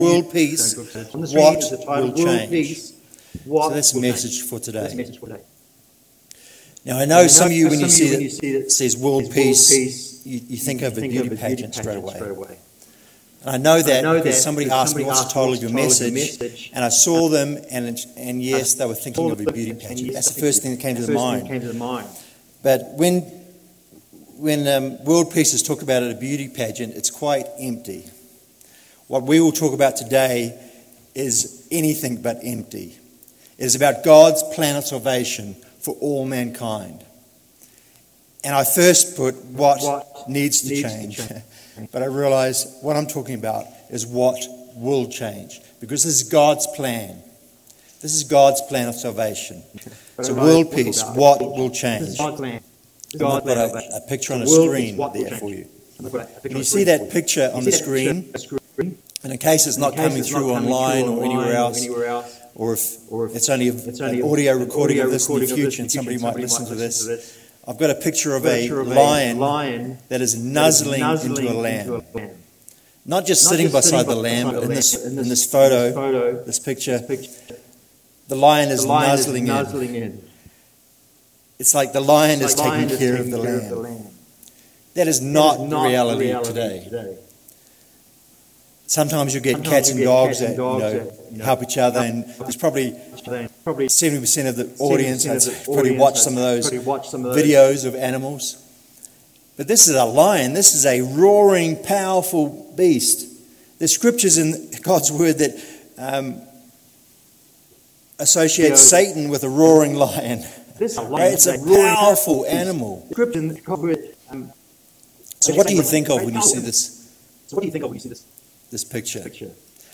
This sermon is about what will change when world peace will be established.
This sermon was given at the Estes Park, Colorado 2023 Feast site.